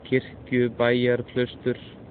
Kirkjubæjarklaustur (Icelandic for "church farm cloister", pronounced [ˈcʰɪr̥cʏˌpaiːjarˌkʰlœystʏr̥]
Is-Kirkjubæjarklaustur_pronunciation.ogg.mp3